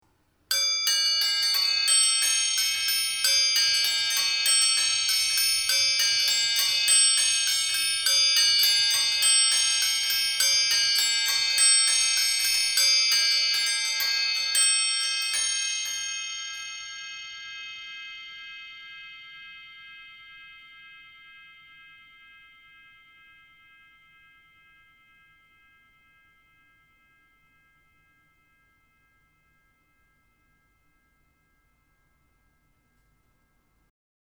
Cymbelstern mit 8 gestimmten Schalenglocken
8 Schalenglocken traditionell aus Glockenbronze gegossen.
Bewegung über Massivholzwelle durch hölzerne Klöppel mit MS-Einlage.
Es findet keine exakte Stimmung wie bei einem Glockenspiel statt, sondern nur eine annähernde.